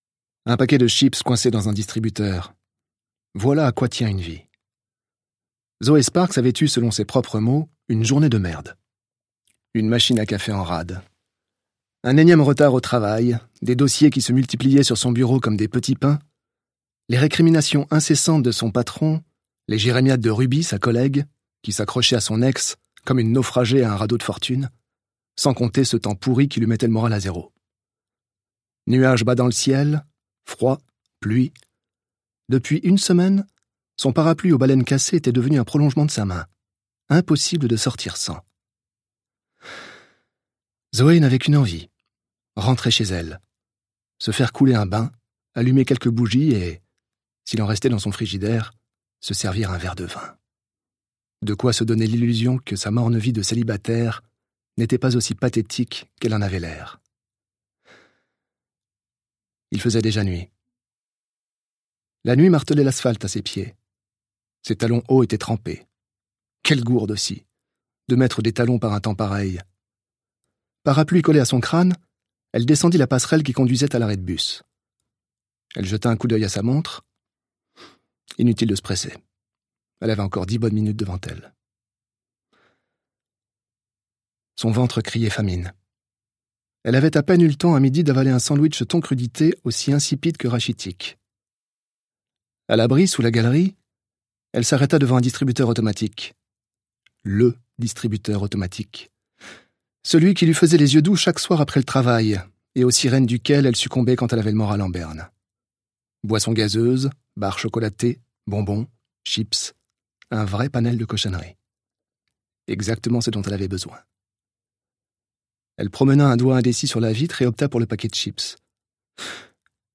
Lire un extrait - Un autre jour de Valentin Musso